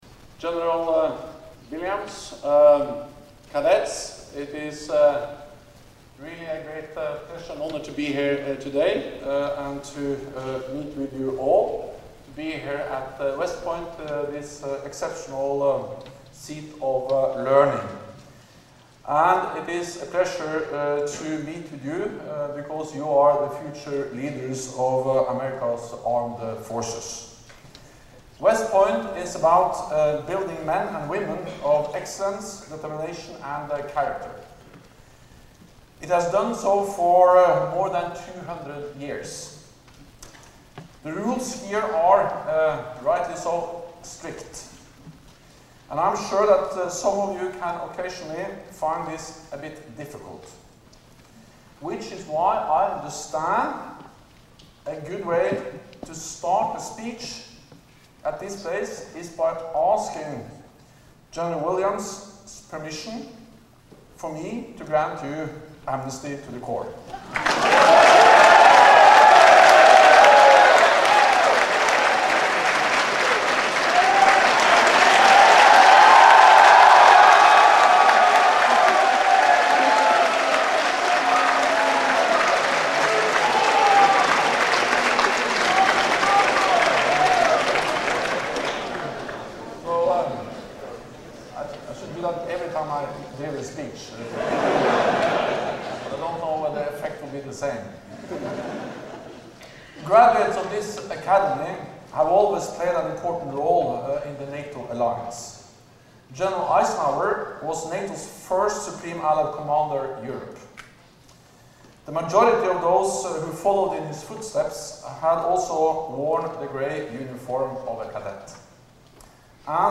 Speech by NATO Secretary General Jens Stoltenberg at the United States Military Academy West Point
(As delivered)